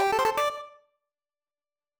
power-up.wav